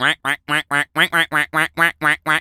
pgs/Assets/Audio/Animal_Impersonations/duck_quack_seq_long_01.wav at master
duck_quack_seq_long_01.wav